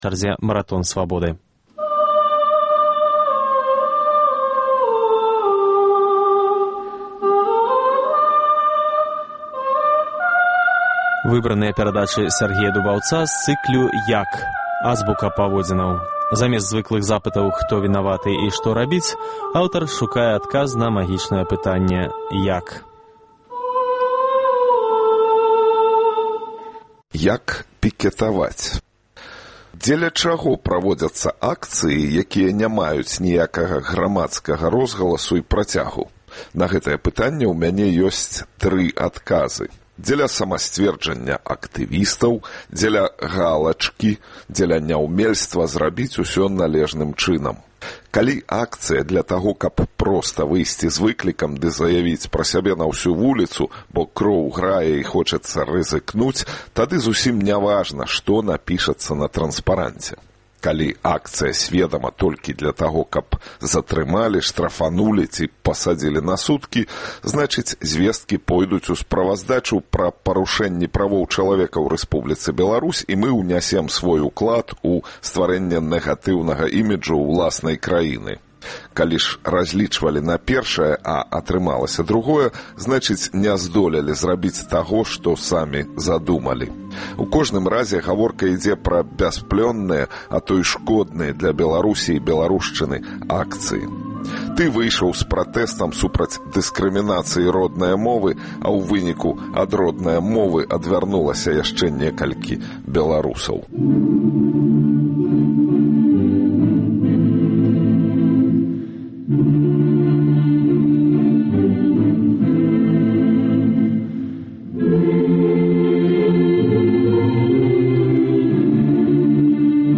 Бясстрашныя эсэ пра мадэлі этыкі для аўтарытарнага грамадзтва. Замест адказаў на звыклыя пытаньні "хто вінаваты" і "што рабіць" аўтар шукае адказ на магічнае пытаньне "як". Сёньня гучыць эсэ "Як пікетаваць?".